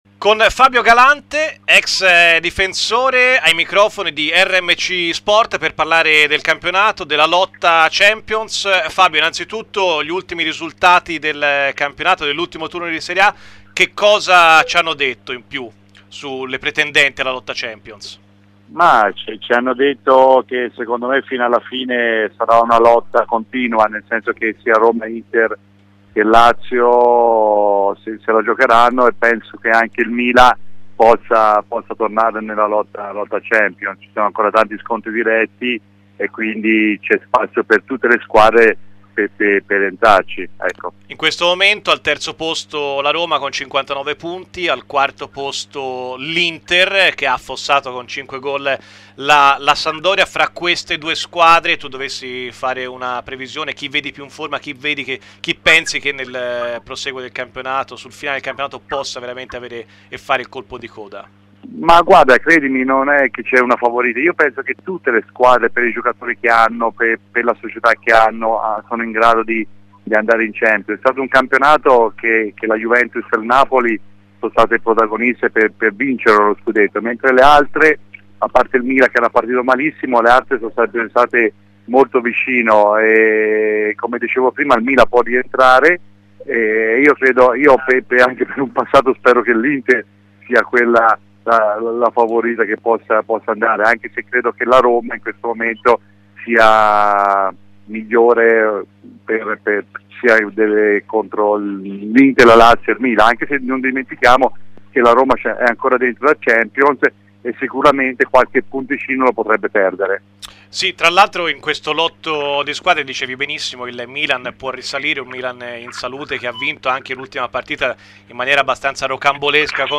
Fabio Galante, ex difensore, sulla lotta Champions e sul momento del Torino. Intervista